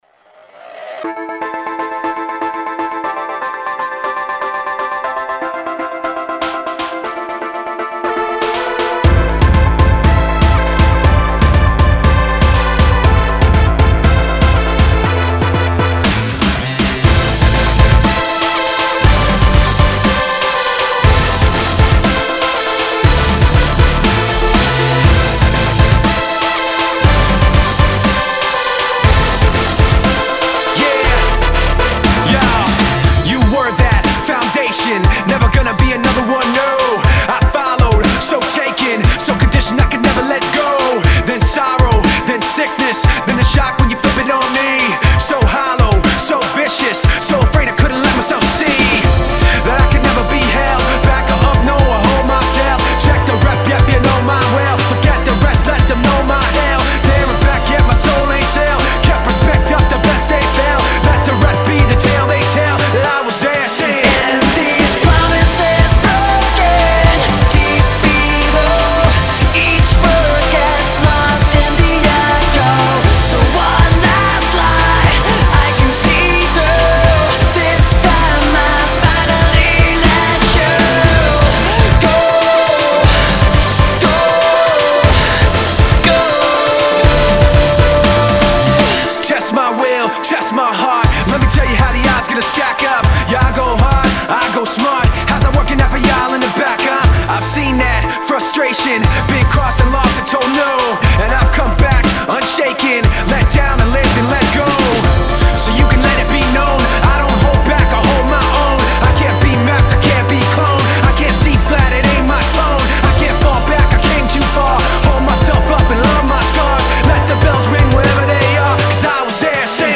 Главная » Файлы » Рок музыка